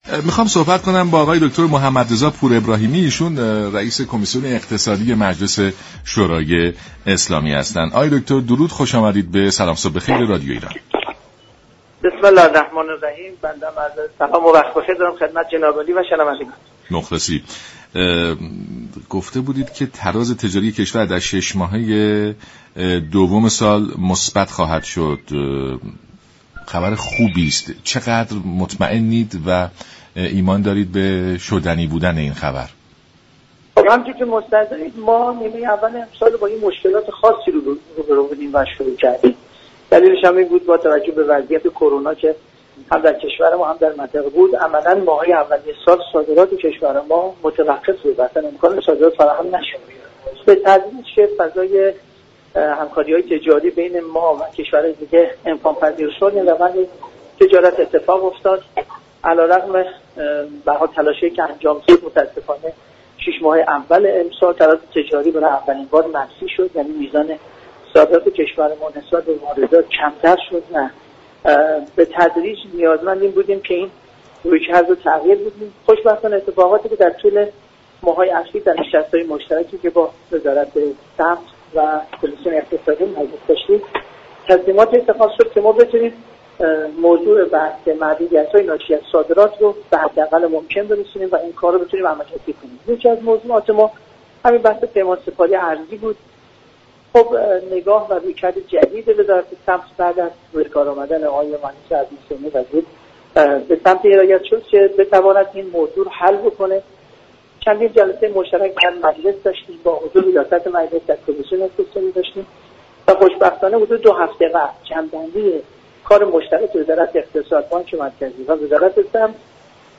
رییس كمیسیون اقتصادی مجلس در برنامه سلام صبح بخیر رادیو ایران گفت: پیش بینی می شود تداوم روند افزایش صادرات، تراز تجاری كشور را در نیمه دوم سال مثبت كند.